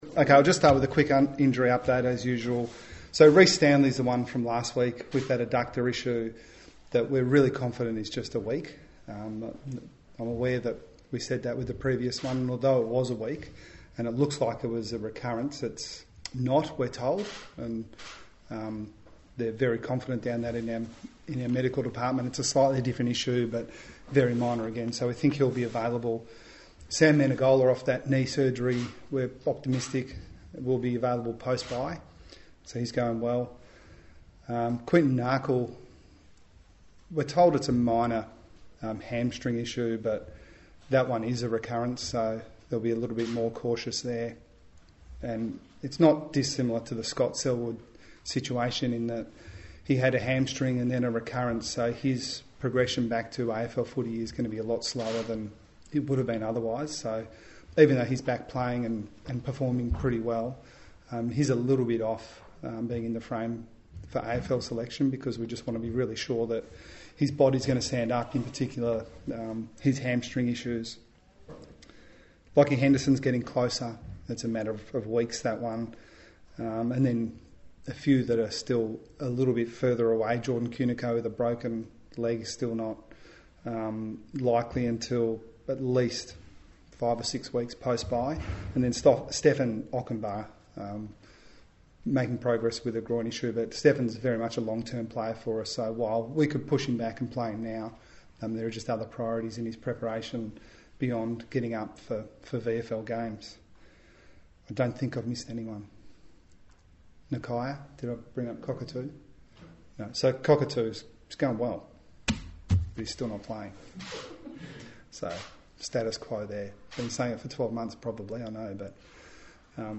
Geelong coach Chris Scott faced the media on Tuesday ahead of Friday night's clash with Richmond.